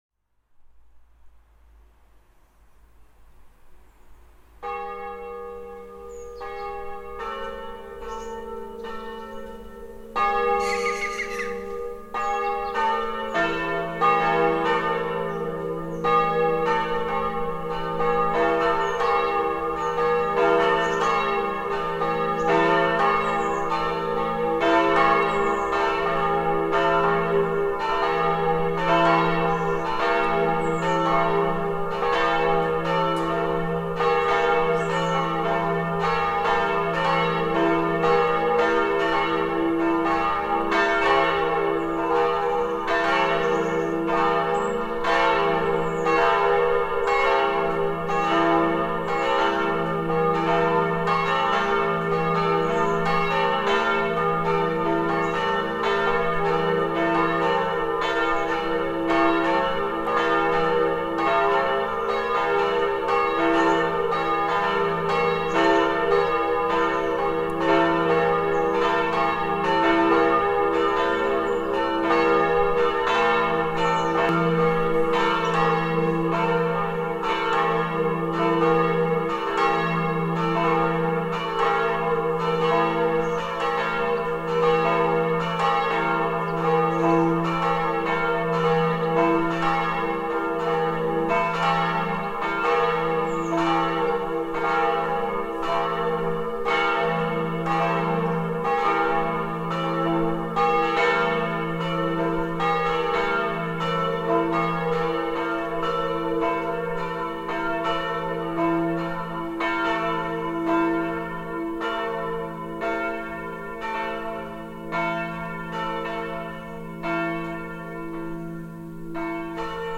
Die Glocken von St. Ulrich
Als Ton hat die Glocke ein eingestrichenes B.
Als Ton hat sie das eingestrichene As.
Ihr Ton ist das eingestrichene F.
st-ulrich-vollgelaut.mp3